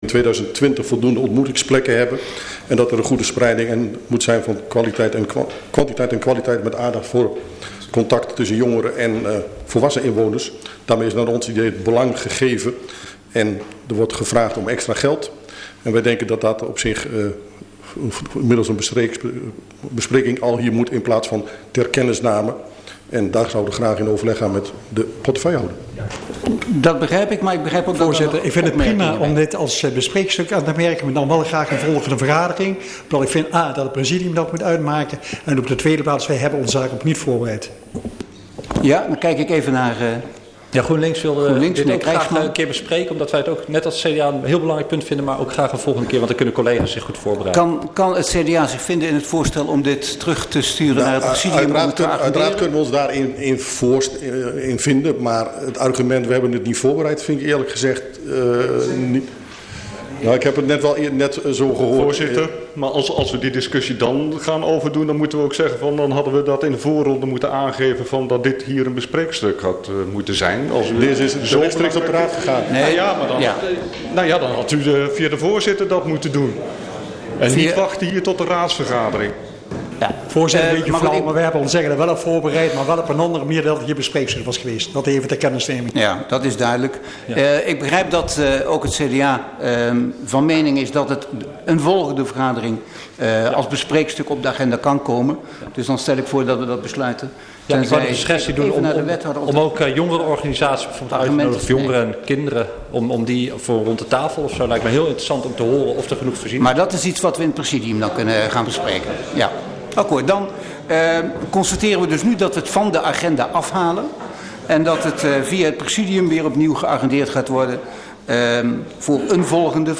Agenda OverBetuwe - Gemeenteraadsvergadering dinsdag 19 september 2017 21:30 - 22:15 - iBabs Publieksportaal
Locatie Raadszaal Elst Voorzitter dhr. A.S.F. van Asseldonk